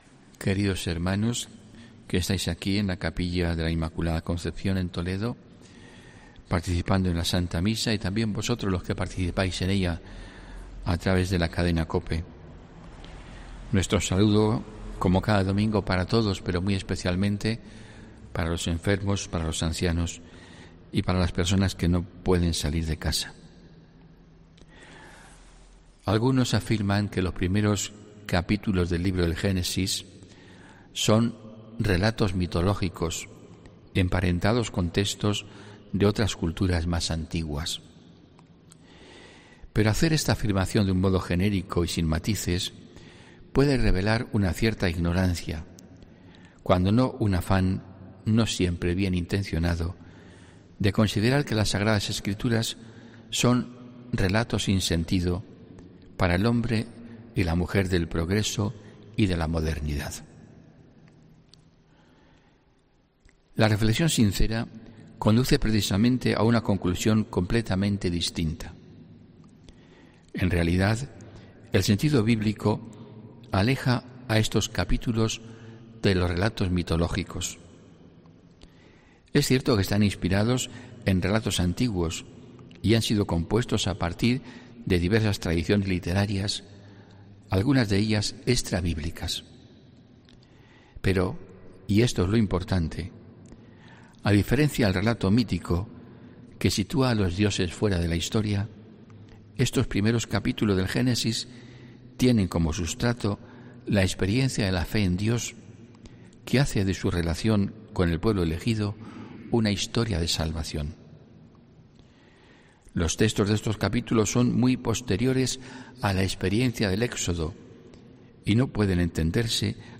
Homilía HOMILÍA 3 OCTUBRE